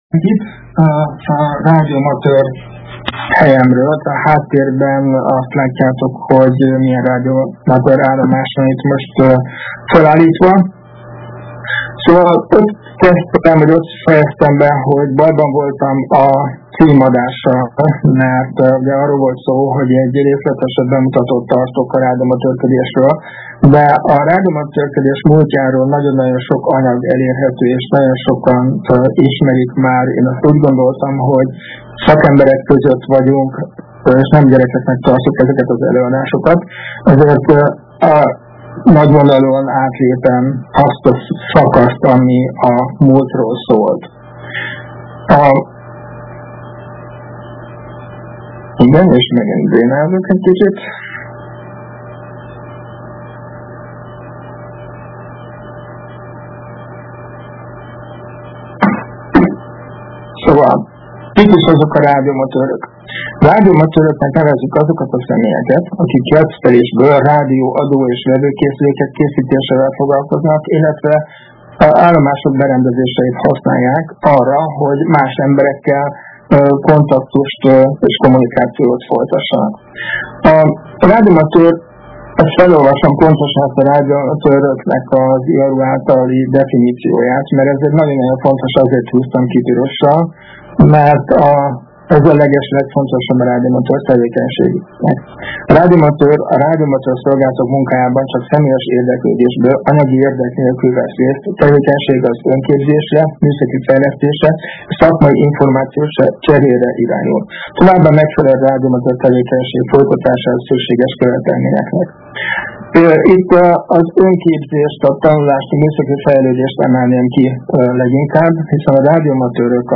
A Vételtechnikai és a Kábeltelevíziós Szakosztály valamint a Médiaklub meghívja az érdeklődőket az alábbi érdekes és aktuális előadásra